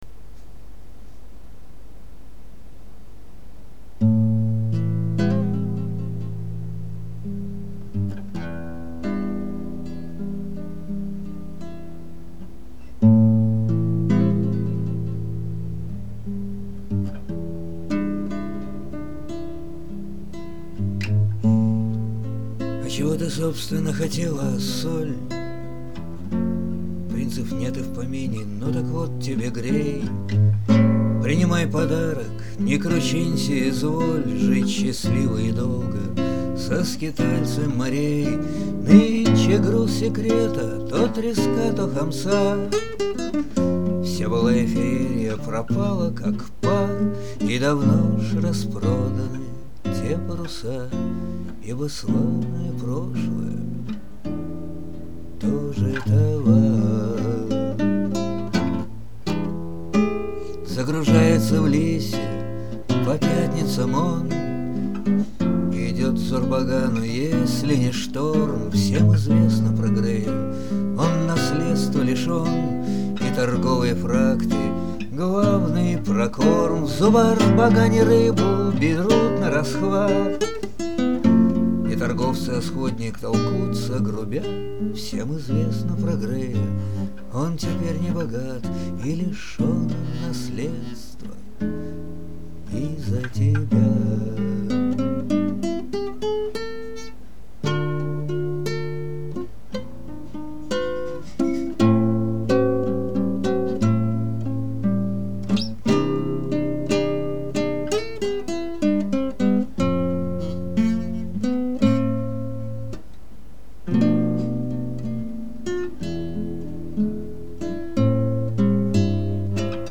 в виде песни с музыкой